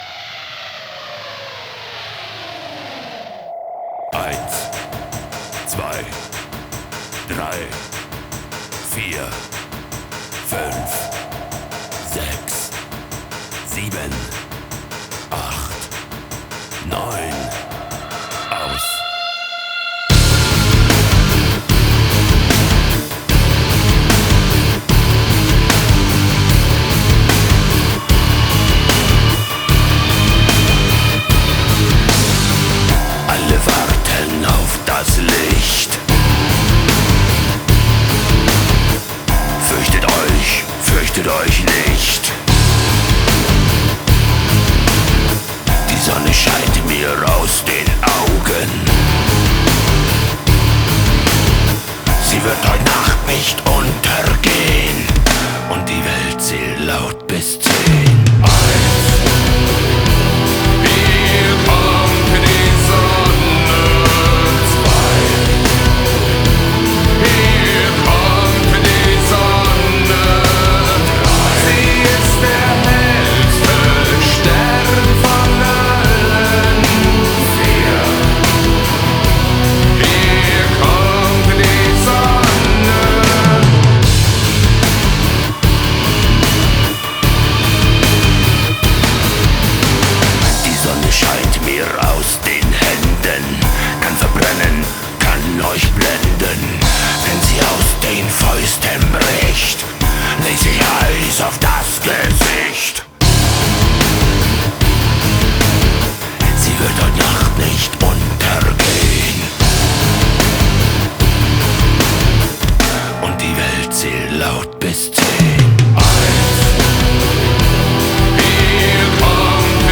2025-01-03 17:33:01 Gênero: Rock Views